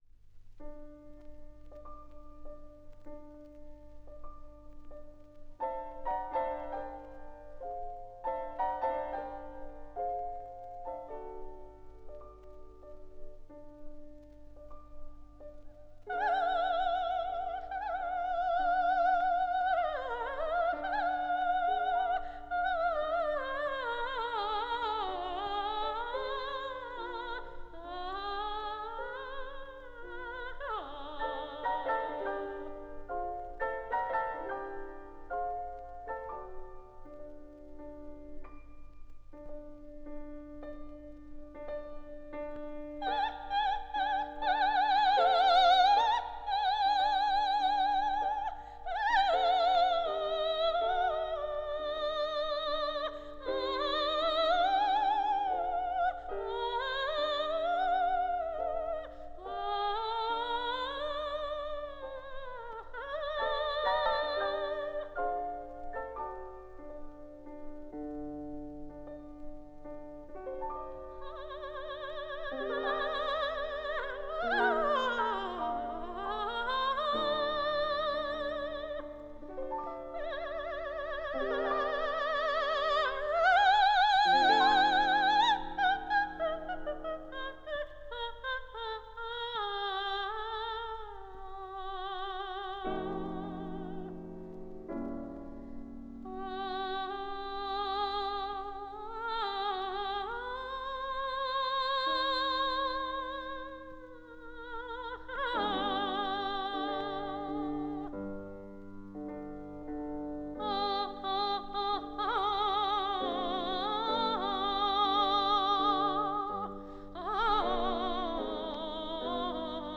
фп.) - Хабанера, вокализ (Морис Равель) (1954)